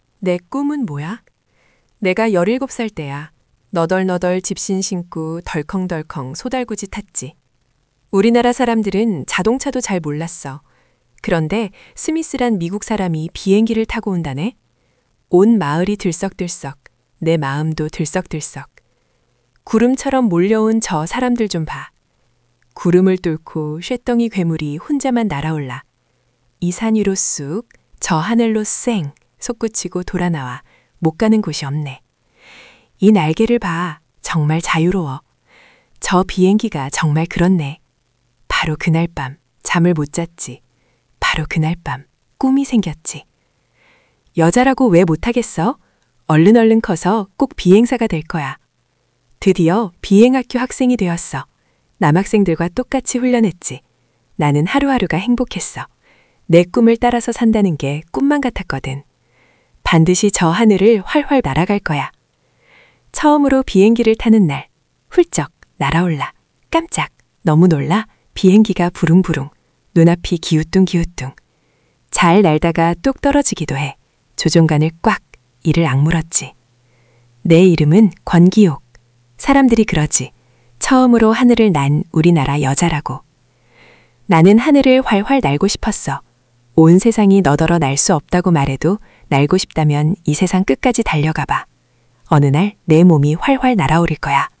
―課題文音声（発音参考用）―